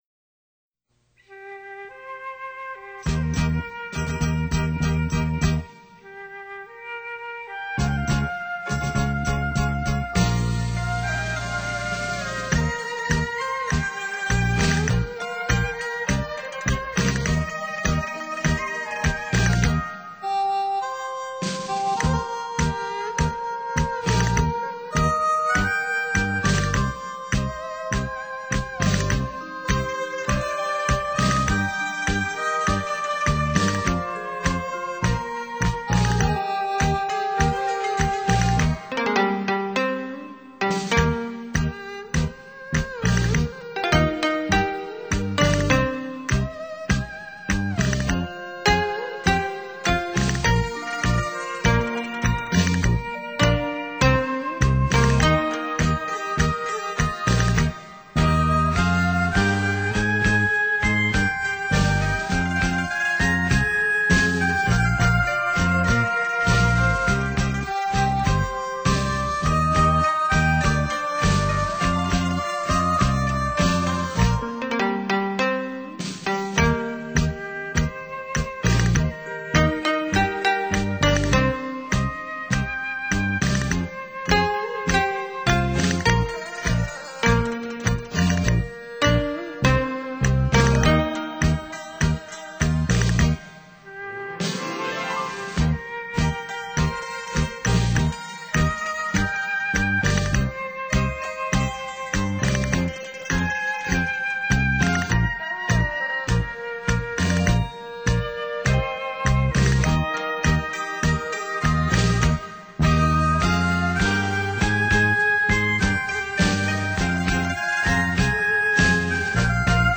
扬琴,琵琶,笛,箫,笙等配合西乐合奏出雅俗共赏的音乐~